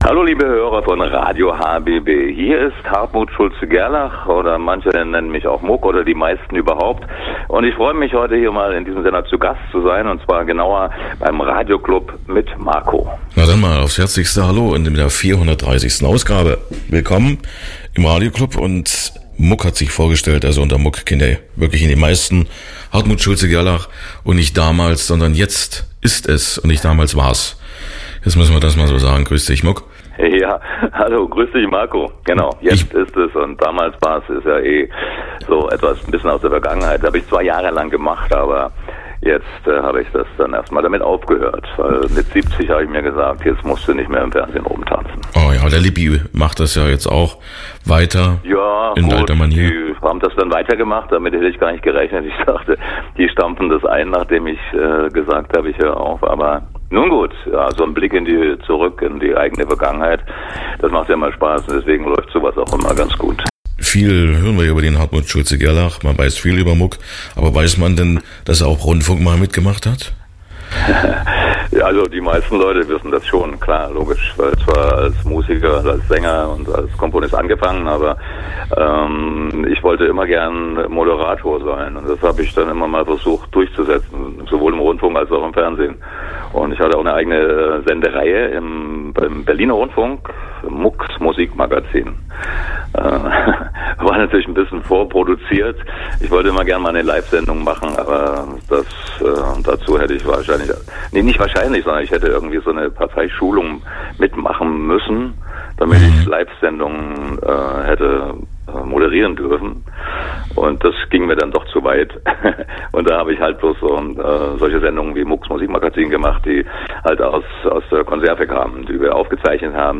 Hartmut Schulze-Gerlach als Muck zu Gast im Radioclub